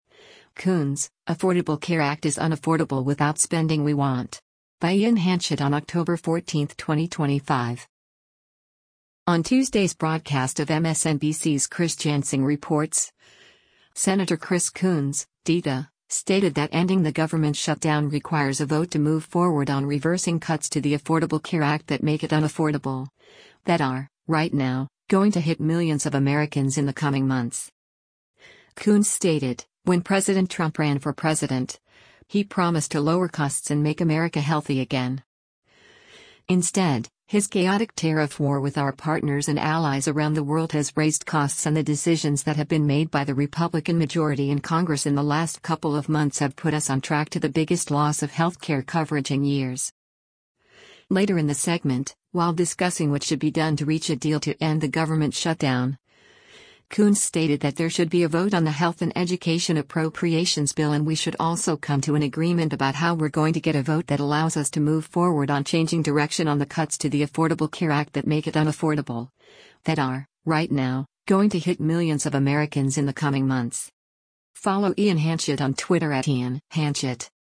On Tuesday’s broadcast of MSNBC’s “Chris Jansing Reports,” Sen. Chris Coons (D-DE) stated that ending the government shutdown requires a vote to move forward on reversing “cuts to the Affordable Care Act that make it unaffordable, that are, right now, going to hit millions of Americans in the coming months.”